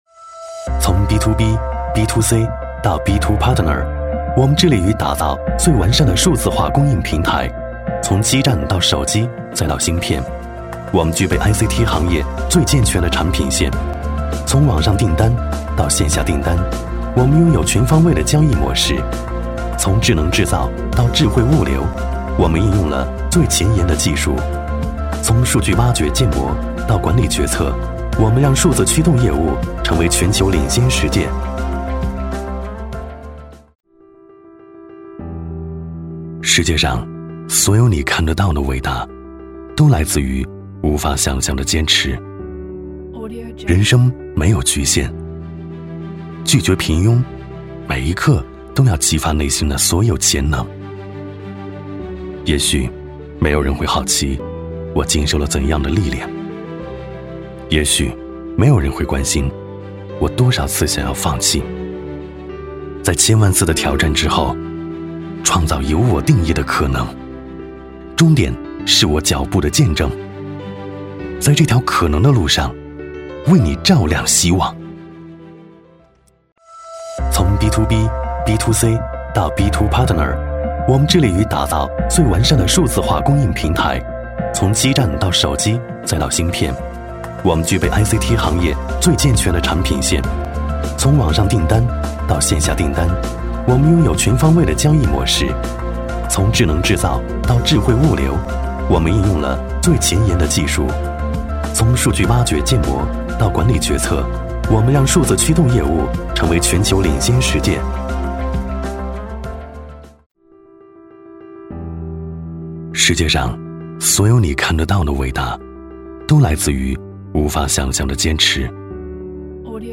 职业配音员全职配音员温柔磁性
• 男S356 国语 男声 广告-坚持-内心、质感 激情激昂|大气浑厚磁性|科技感|积极向上